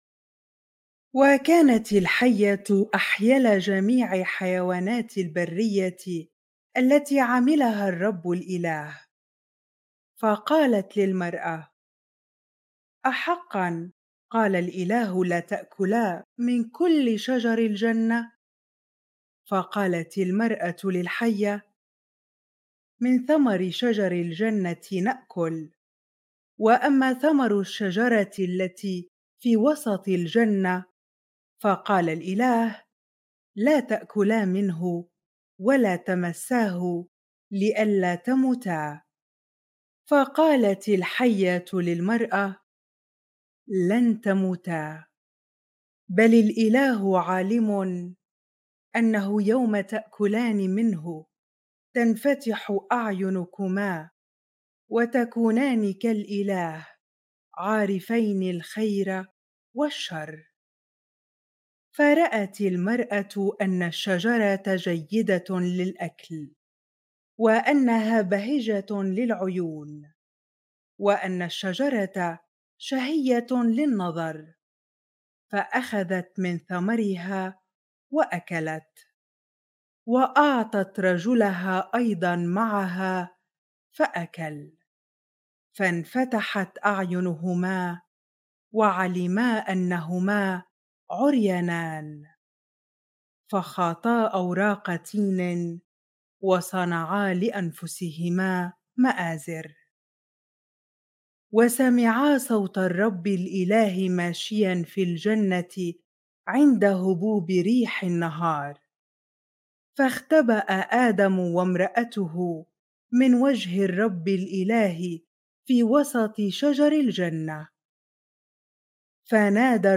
bible-reading-genesis 3 ar